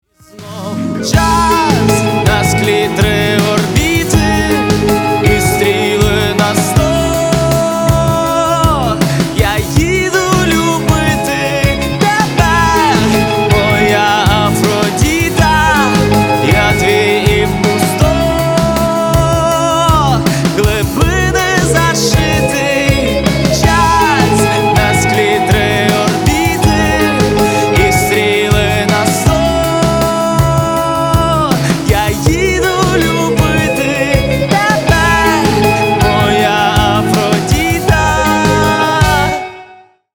мужской вокал
красивые
спокойные
Pop Rock
украинский рок